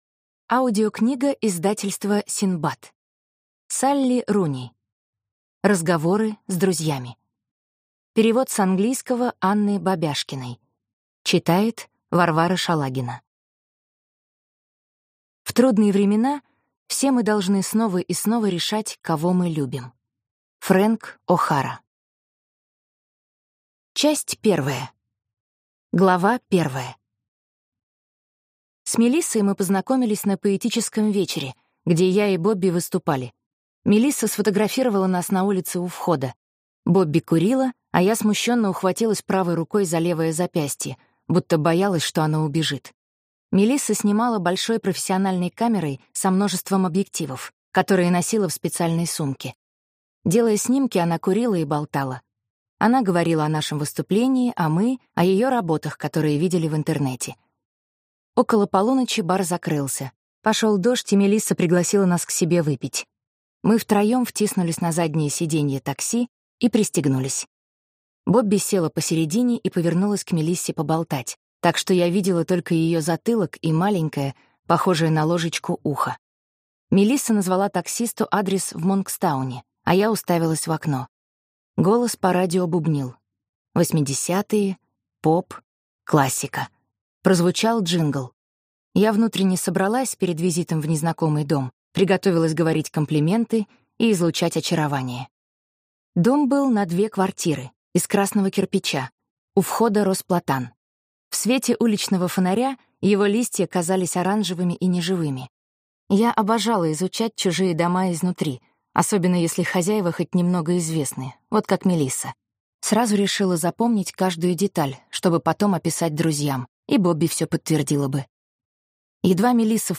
Аудиокнига Разговоры с друзьями | Библиотека аудиокниг